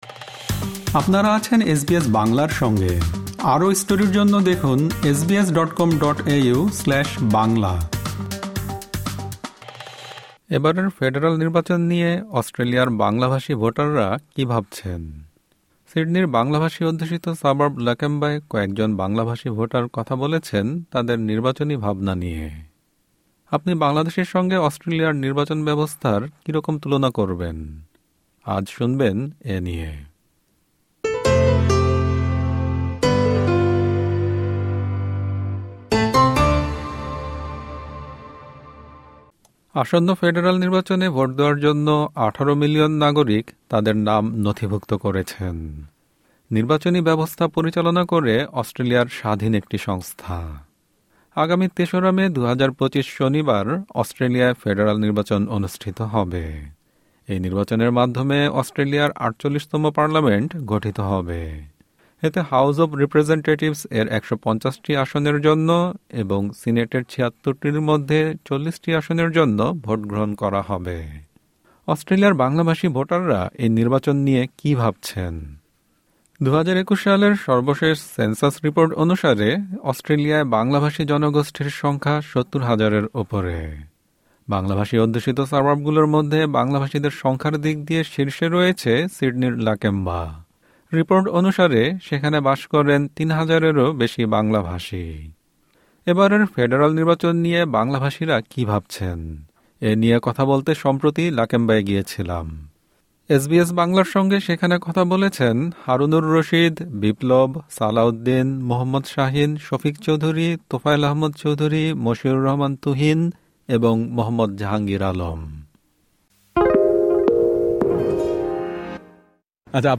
এবারের ফেডারাল নির্বাচন নিয়ে অস্ট্রেলিয়ার বাংলাভাষী ভোটাররা কী ভাবছেন? সিডনির বাংলাভাষী অধ্যুষিত সাবার্ব লাকেম্বায় কয়েকজন বাংলাভাষী ভোটার কথা বলেছেন তাদের নির্বাচনী ভাবনা নিয়ে।